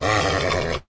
sounds / mob / horse / angry1.ogg
angry1.ogg